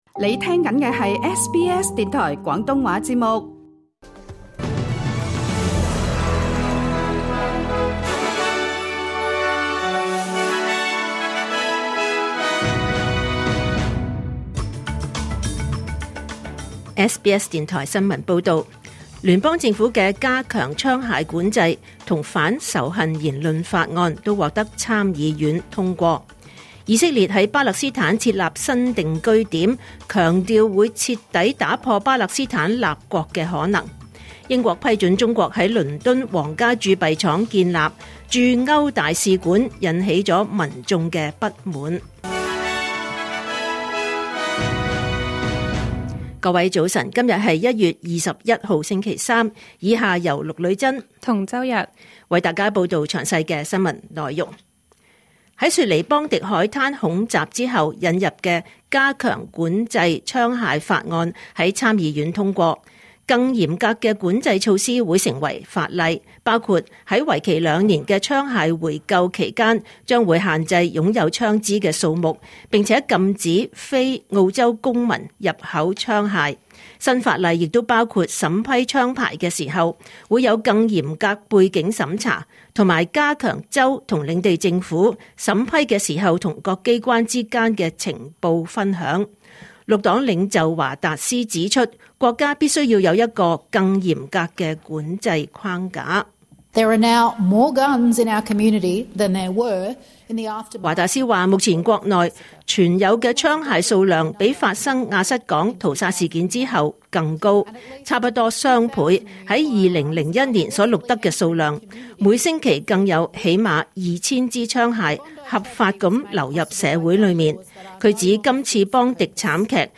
2026年1月21日SBS廣東話節目九點半新聞報道。